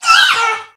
Cri de Wushours dans Pokémon Épée et Bouclier.